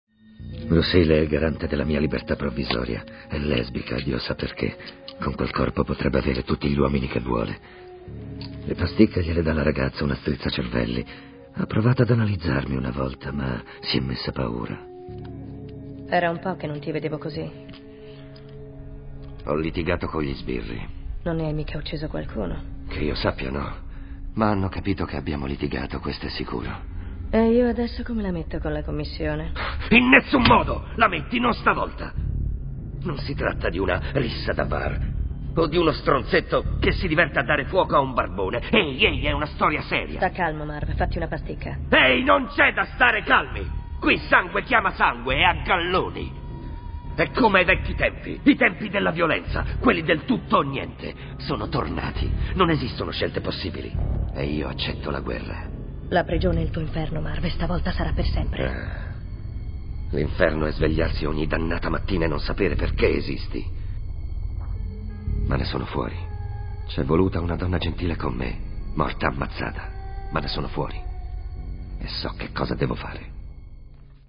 nel film "Sin City", in cui doppia Mickey Rourke.